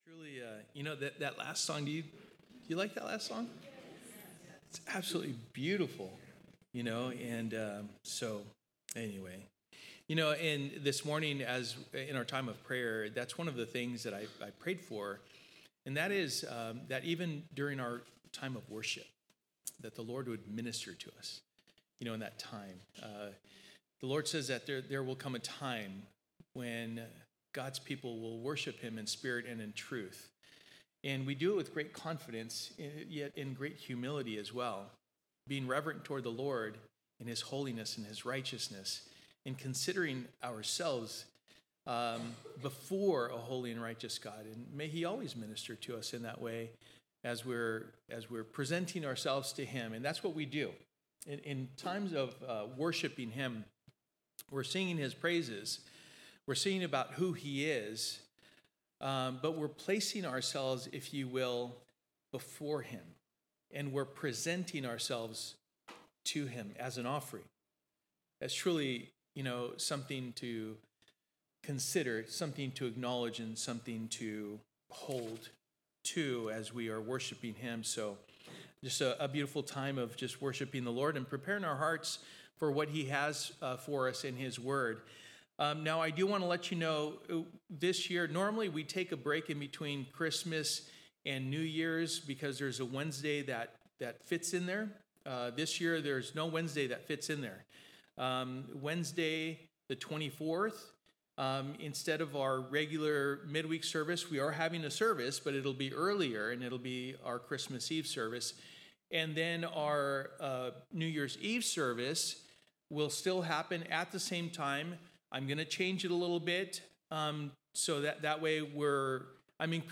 James Passage: James 4:1-17 Service: Sunday Morning « Wisdom